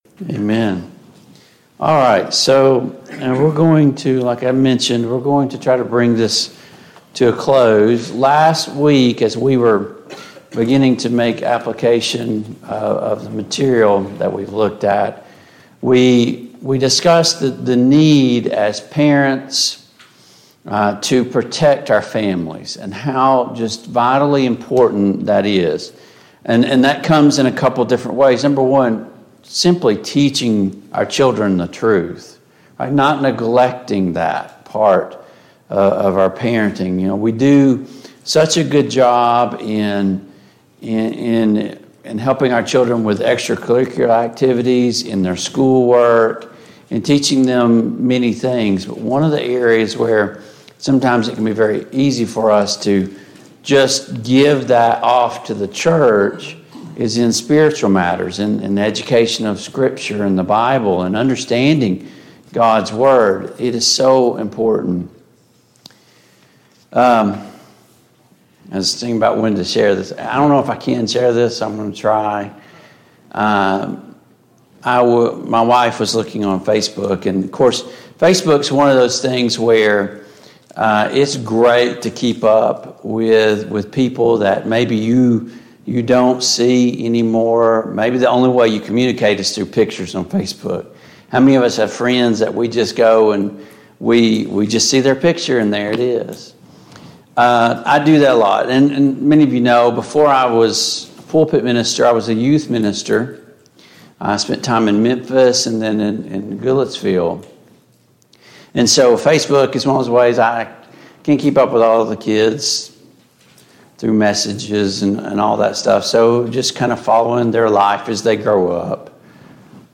Romans 12:17-21 Service Type: Sunday Morning Bible Class Topics